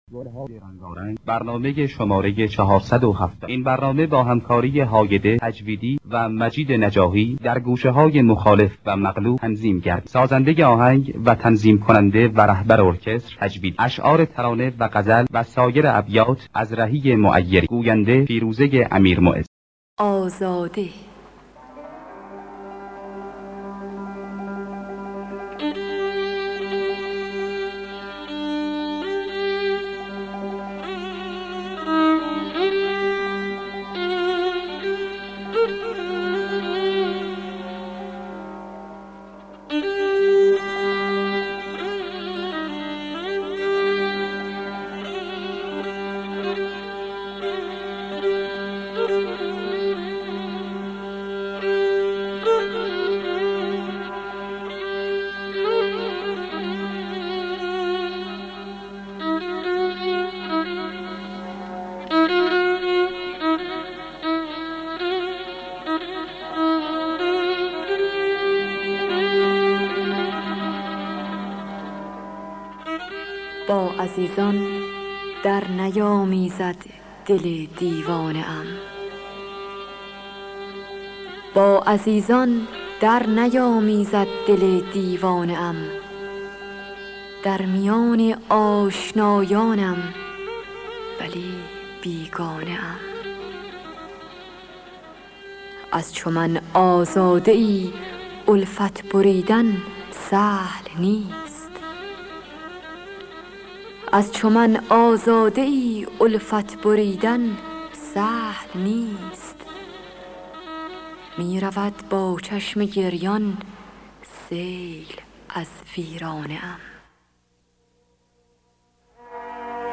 گلهای رنگارنگ ۴۷۰ - سه‌گاه